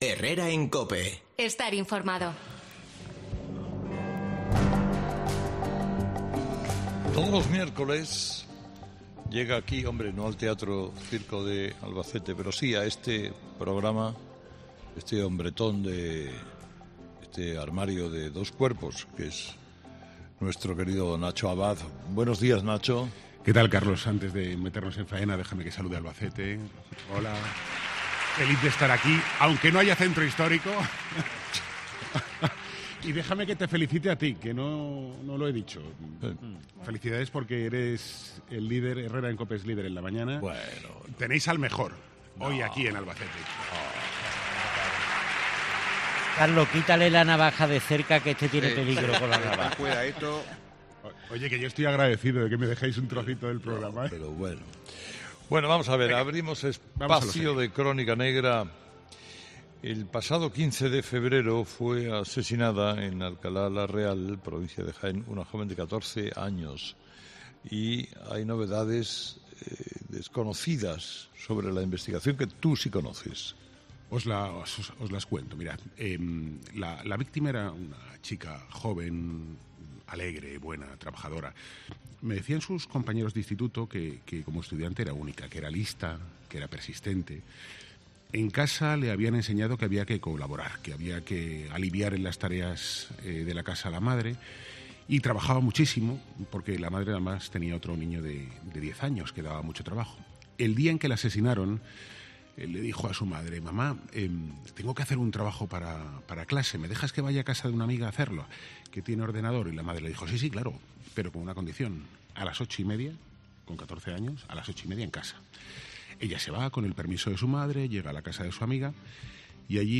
Como cada miércoles, Nacho Abad ha pasado por los micrófonos de Herrera en COPE para traer la última hora de la crónica negra de nuestro país; y en esta ocasión, para traer de la mano una exclusiva relacionada con el atestado policial del crimen de Alcalá de la Real.